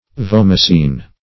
Vomicine \Vom"i*cine\, n.